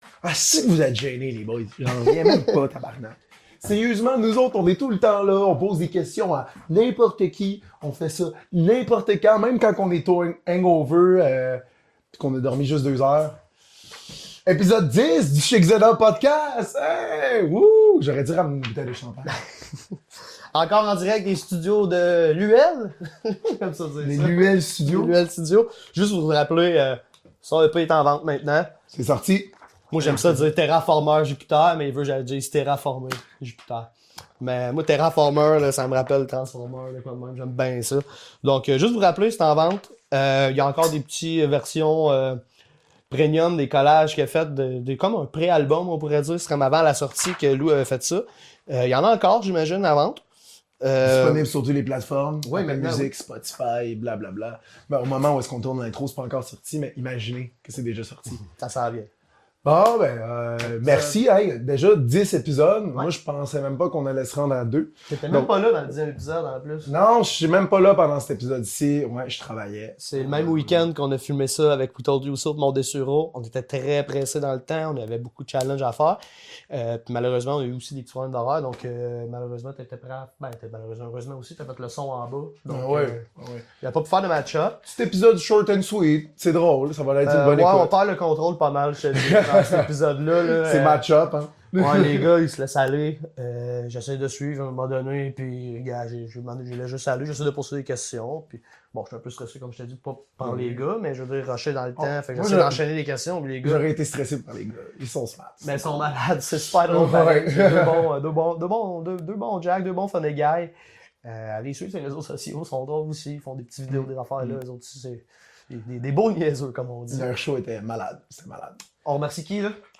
Cette semaine on recoit le groupe punk acoustique The Matchup!Enregistré dans le cadre de leur venu au Café-Bar Zénob le 15 mars 2025